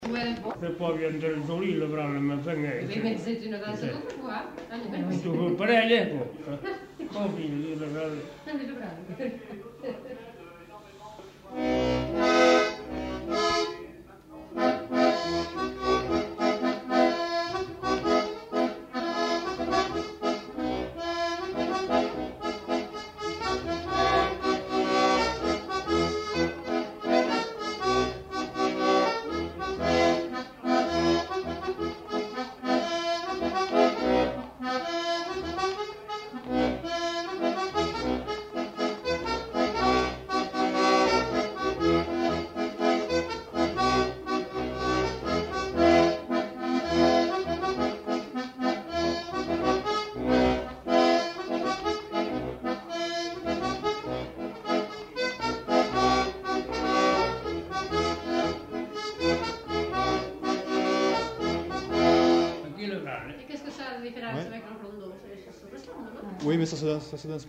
Lieu : Pavie
Genre : morceau instrumental
Instrument de musique : accordéon diatonique
Danse : borregada